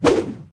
footswing3.ogg